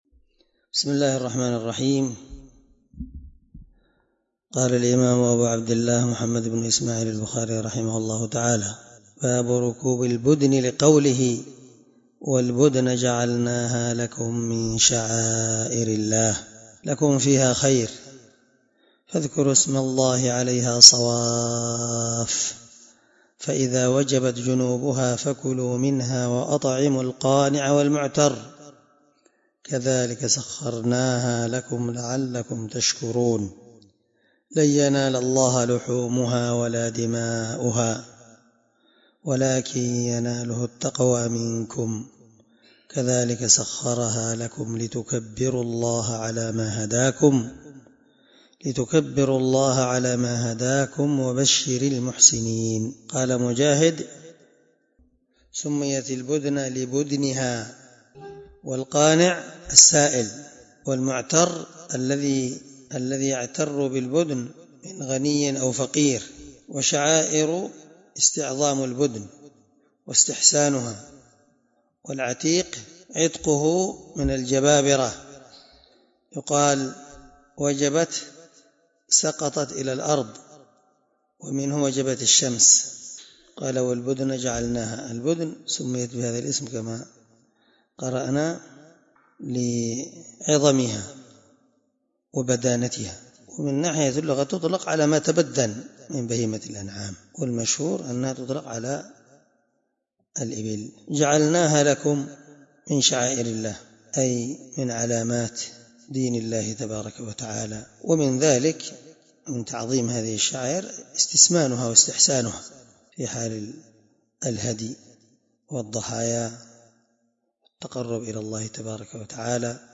الدرس70 من شرح كتاب الحج حديث رقم(1689-1690 )من صحيح البخاري